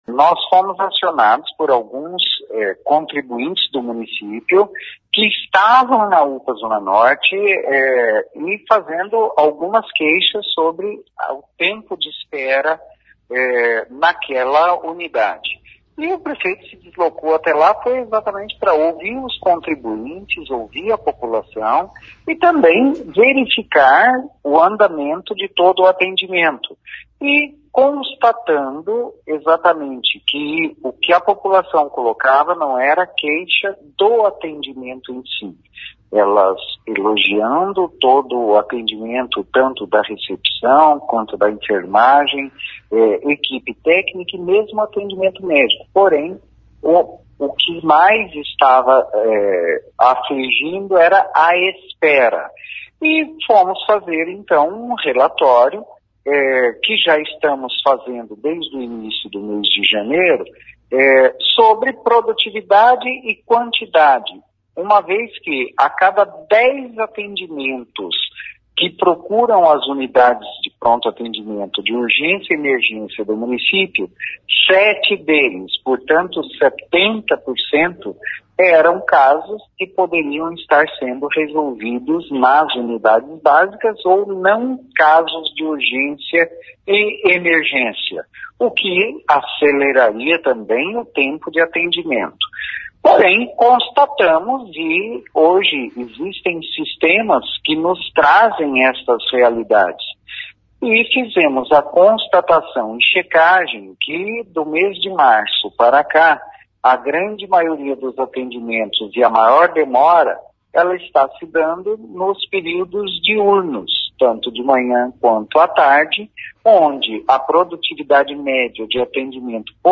Secretário de saúde explica baixa produtividade de equipes médicas